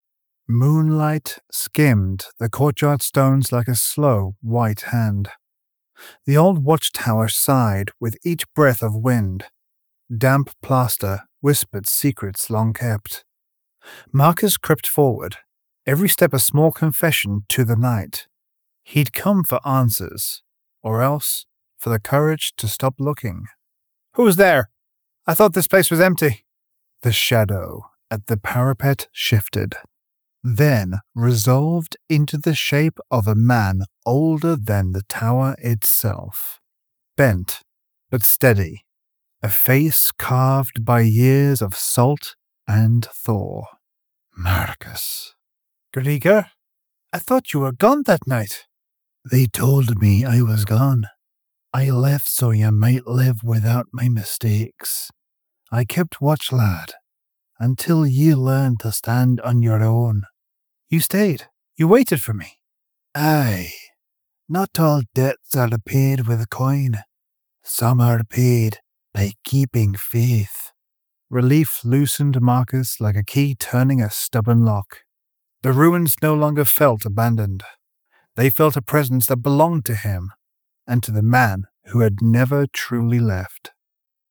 Standard Tokyo accent. 20s to 50s baritone range. Warm and authentic for narrations, uplifting and engaging for commercials, smooth and professional for presentations....
1125Audiobook_Demo.mp3